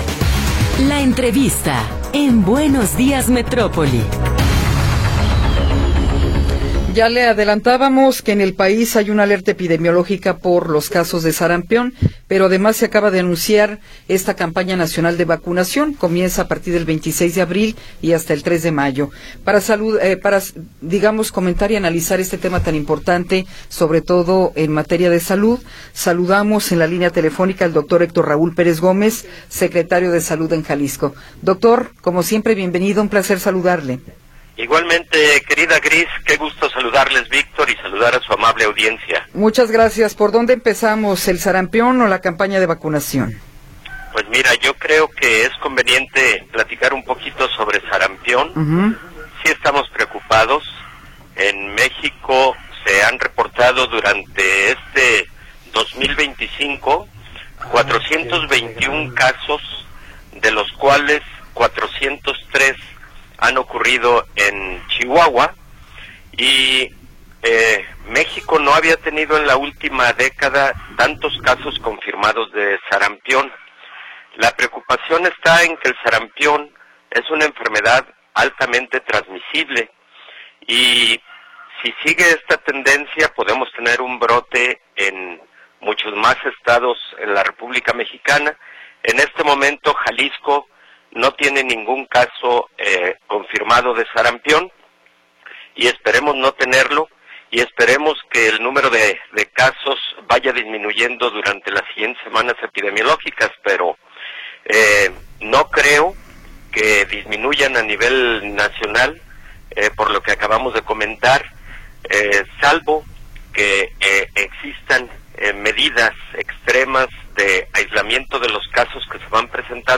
Entrevista con el Dr. Héctor Raúl Pérez Gómez